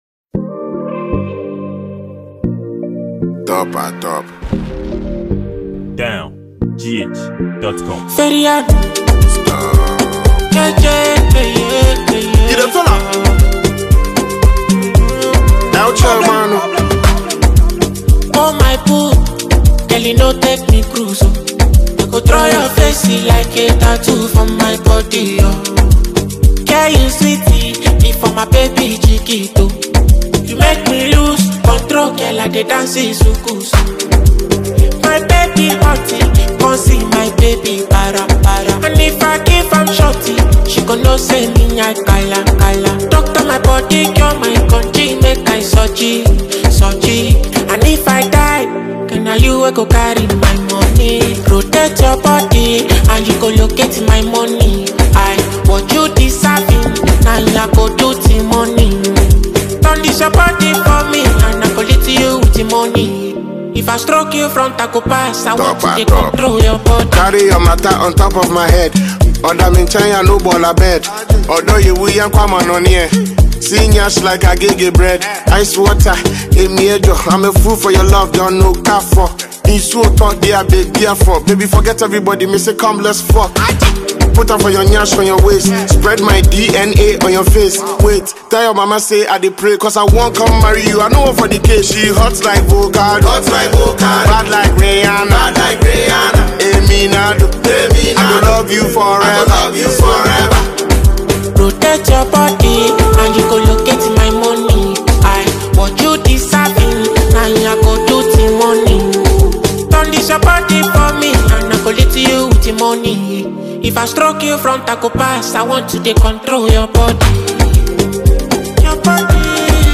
Sensational Afrobeat Ghanaian songwriter and musician
This is a free Mp3 song featuring Ghanaian topper-top rapper